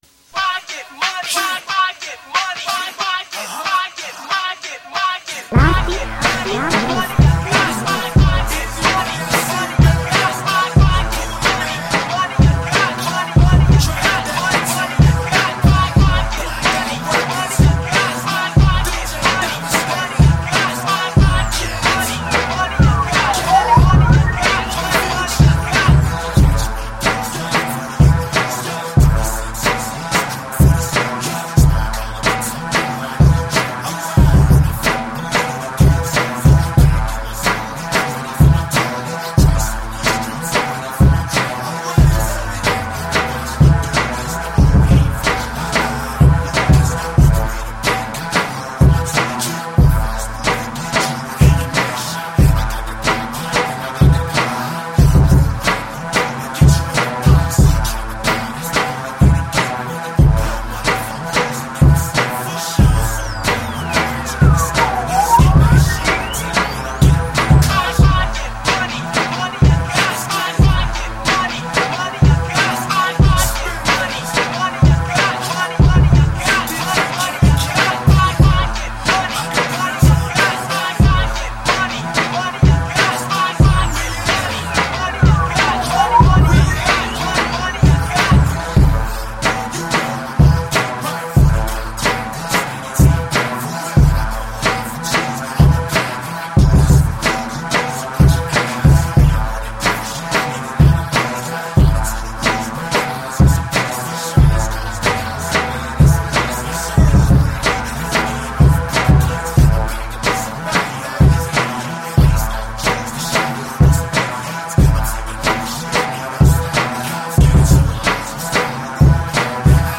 Rap
Hip-hop
Trip-hop